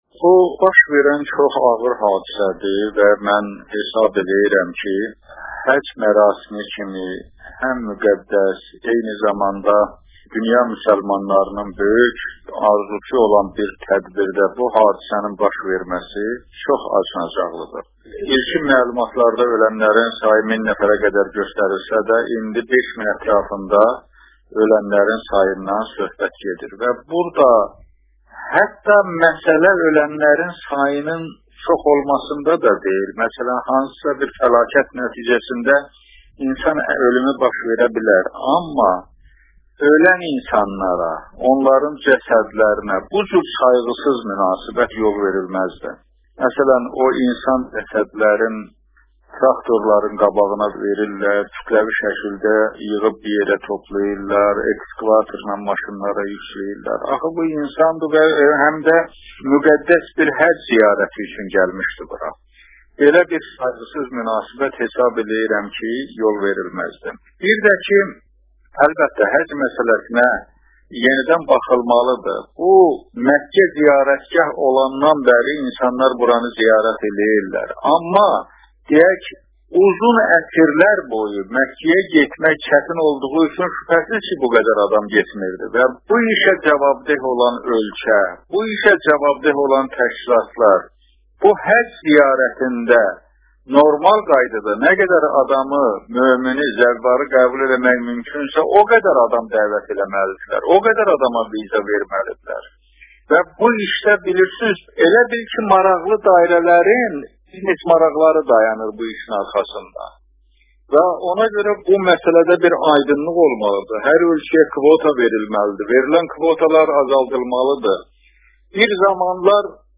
R. Mİlli Şurasının sədri Cəmi l Həsənli azeri radiosu ilə eksklüziv müsahibə də Mina hadisəsi haqda fikirlərini bölüşərək deyib: Mİna ha disəsinin baş verməsi çox acınacaqlıdır.